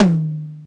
cch_12_percussion_one_shot_tom_high_tamba.wav